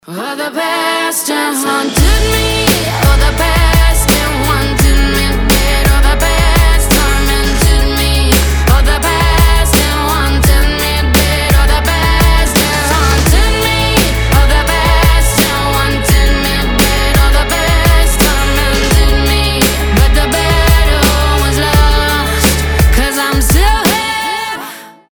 поп
красивая мелодия
красивый женский вокал
сильный голос